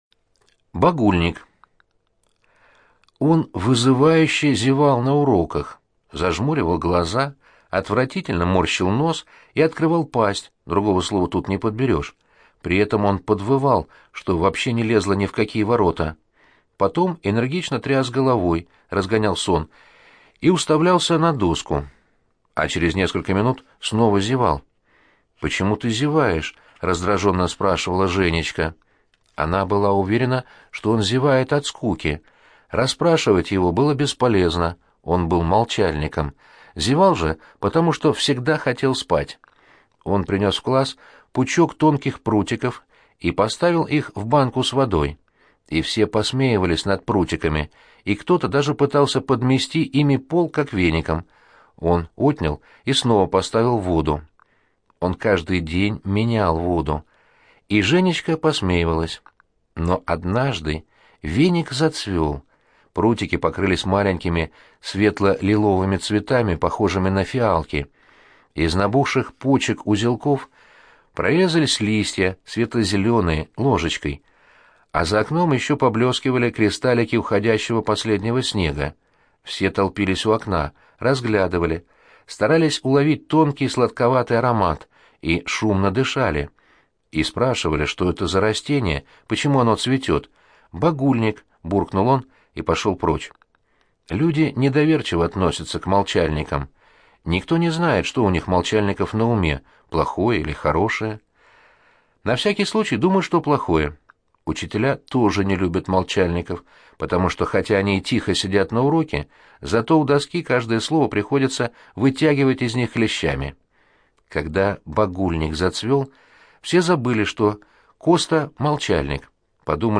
Багульник - Яковлев - слушать рассказ онлайн
Багульник - аудио рассказ Яковлева Ю.Я. Рассказ про мальчика, который был молчуном и часто зевал и засыпал на уроках.